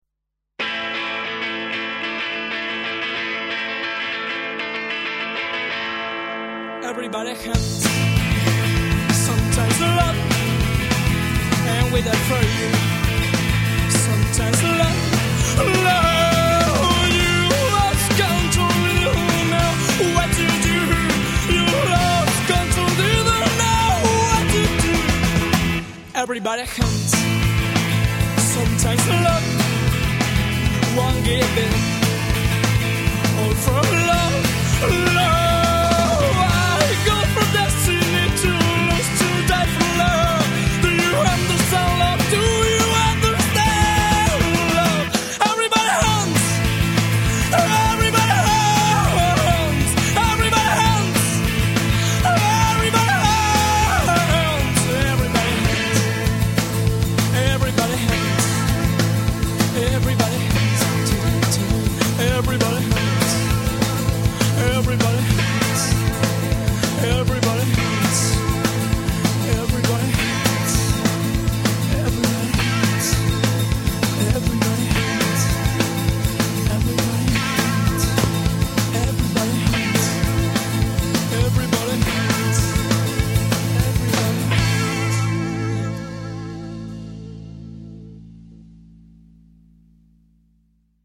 guitarra
bajo
bateria